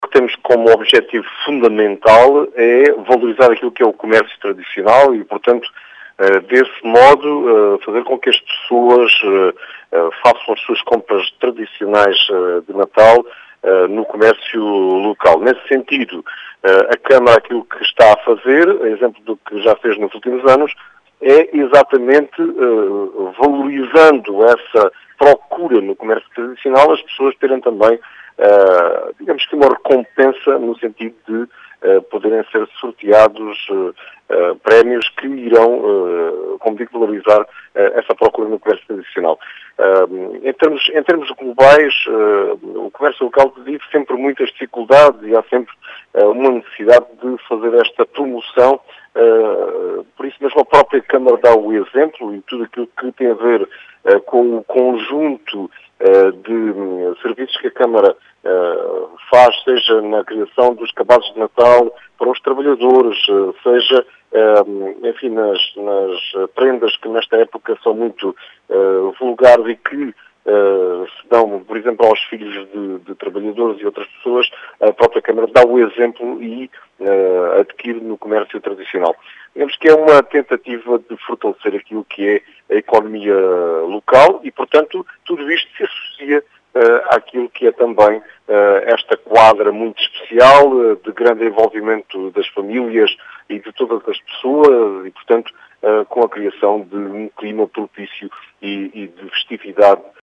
Explicações de António José Brito, presidente da Câmara de Castro Verde, que fala numa “tentativa de fortalecer a economia local”.